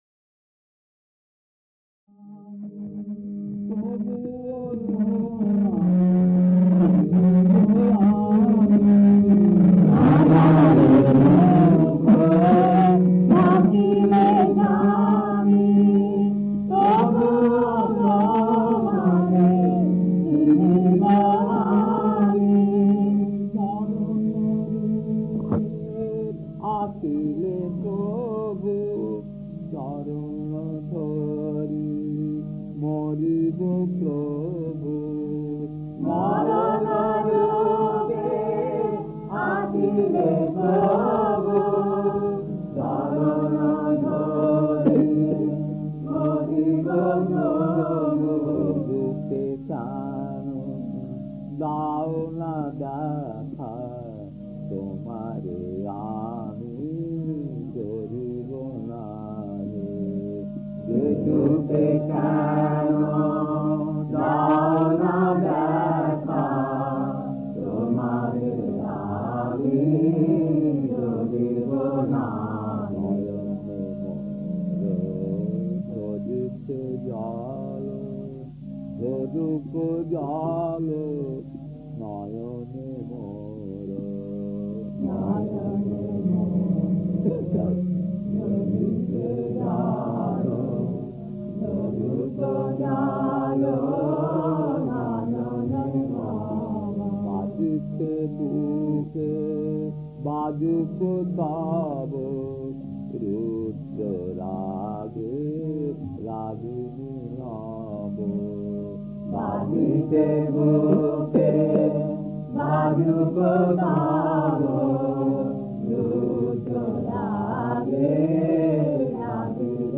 Kirtan C2-1 Puri, 1979, 51 minutes 1.